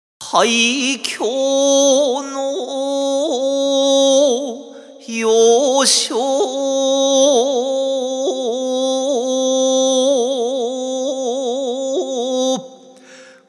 詩吟のウェブ講座を掲載しています。
吟じ始めは力まず普通に。